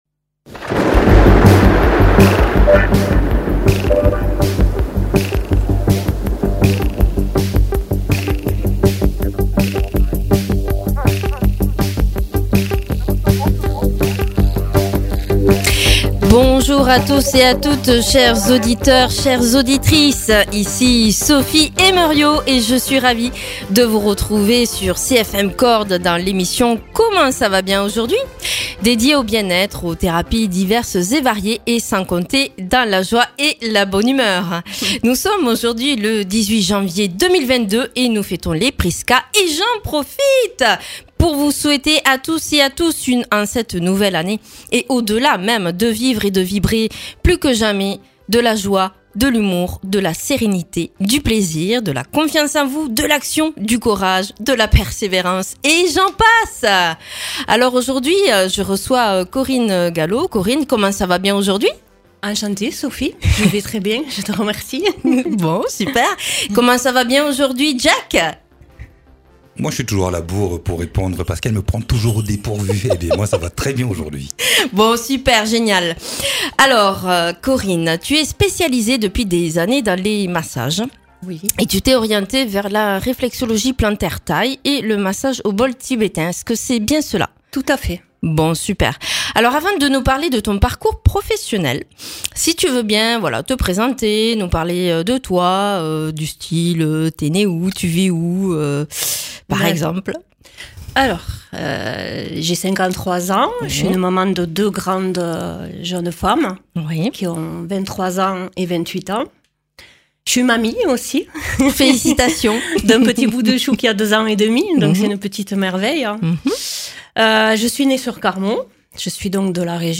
Nous explorerons avec notre invitée la réflexologie et ses massages au bol tibétain